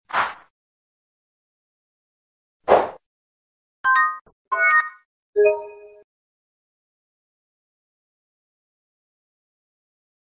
Sony PlayStation Error Evolution 2011-2025